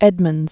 (ĕdməndz)